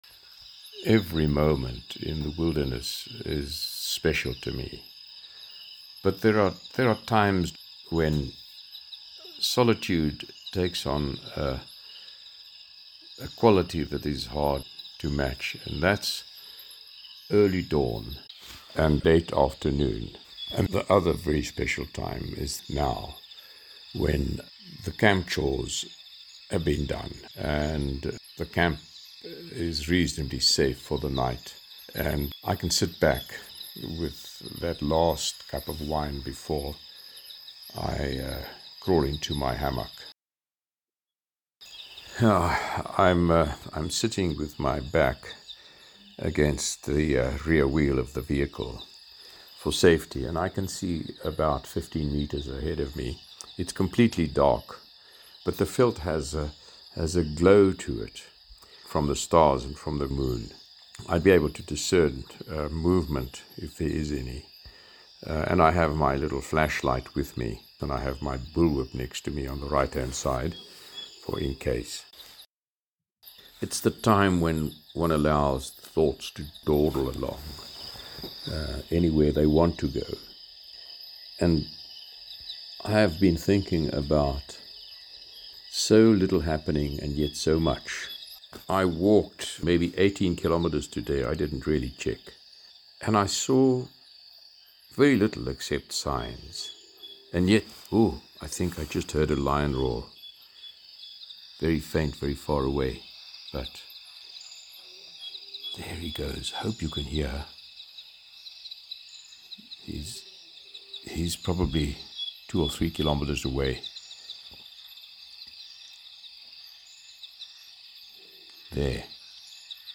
The time after the camp chores had been completed, when I can sit down with a last cup of wine in the dark is special to me.
Thank you, once again it is a simple thing to effortlessly drift along with the thoughts you set afloat with your still voice.